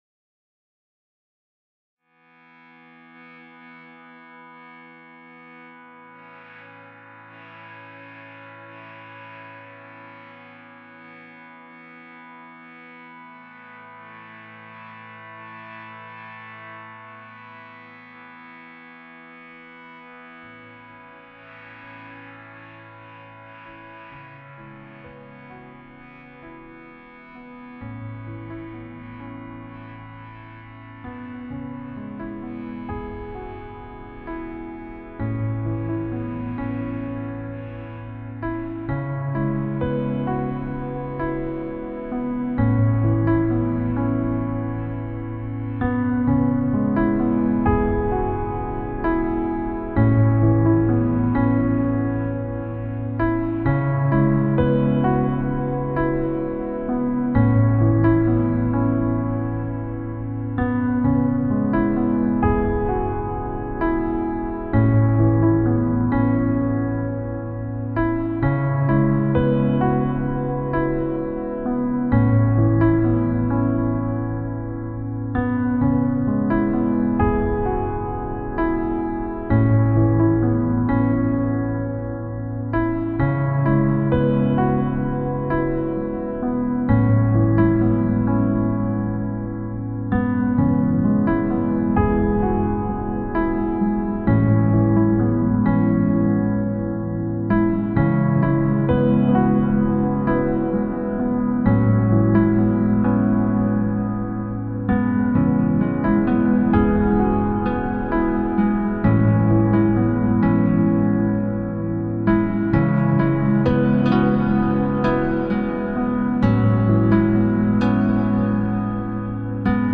Relaxation music, inspired by the picture of Applejack.